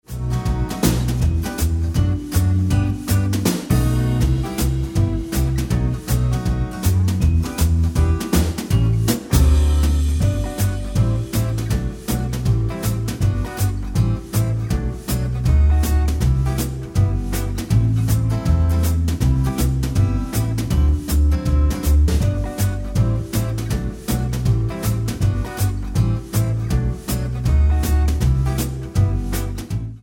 Tonart:G ohne Chor